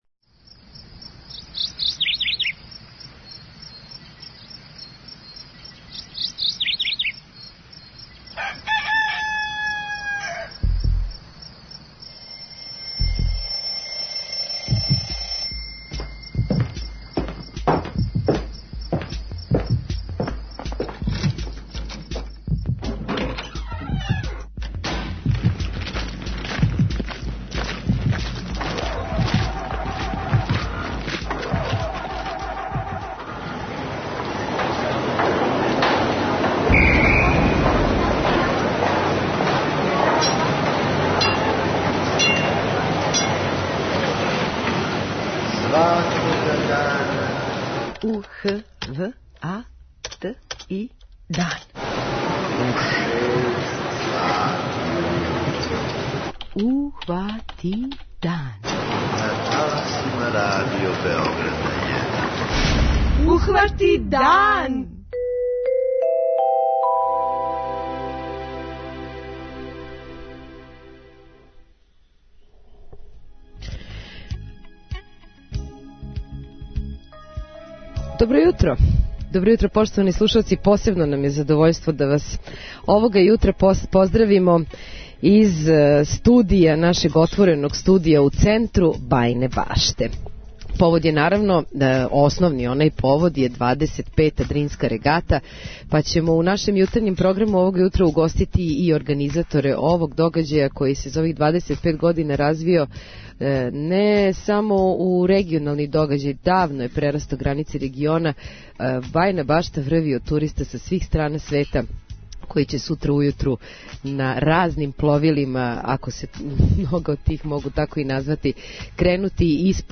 Екипа јутарњег програма овога јутра је у Бајиној Башти, одакле ћемо вам пренети делић атмосфере дан пред чувену Дринску регату, али ћемо вас и упознати са овом варошицом на западу наше земље.